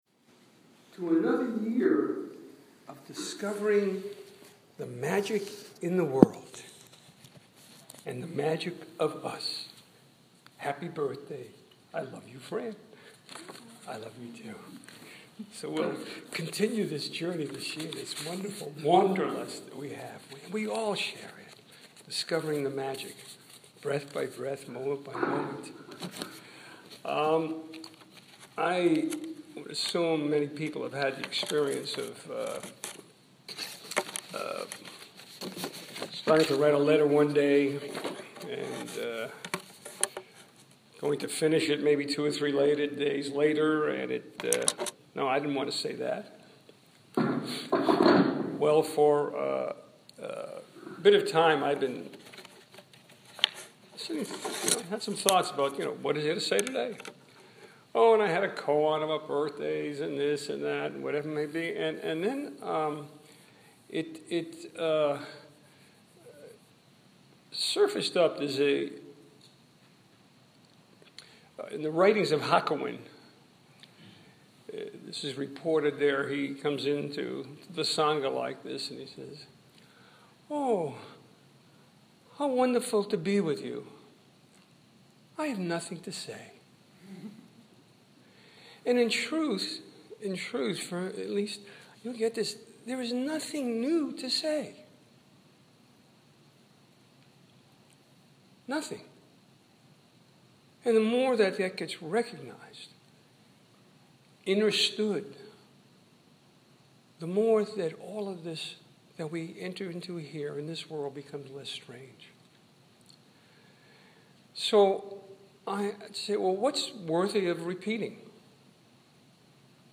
Dharma Talk
August 2015 Southern Palm Zen Group